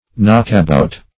knockabout \knock"a*bout`\, a.